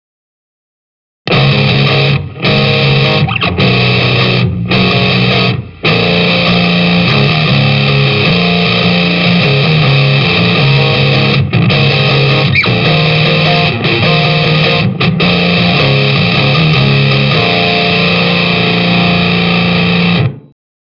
channels.jpg 86,5 KB · Просмотры: 235 left4.jpg 83,2 KB · Просмотры: 242 right4.jpg 75,6 KB · Просмотры: 227 new_metal.mp3 new_metal.mp3 490,4 KB · Просмотры: 249